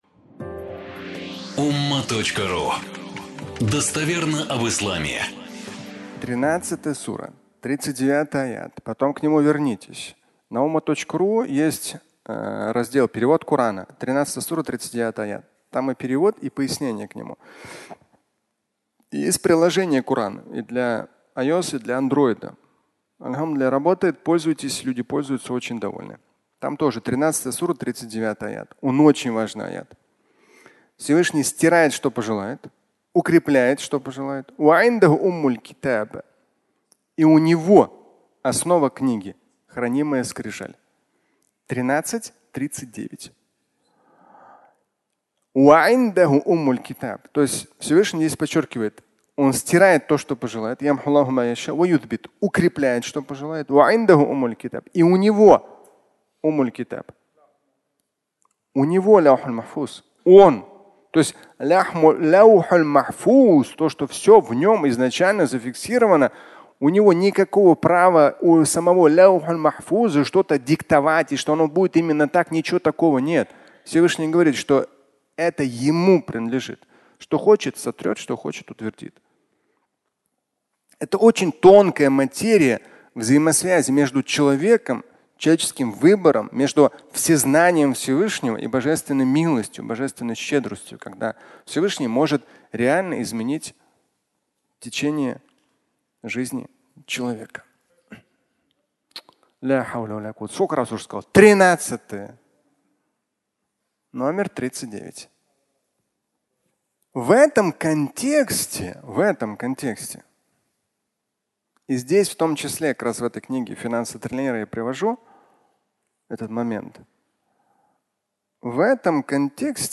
Молитва Умара (аудиолекция)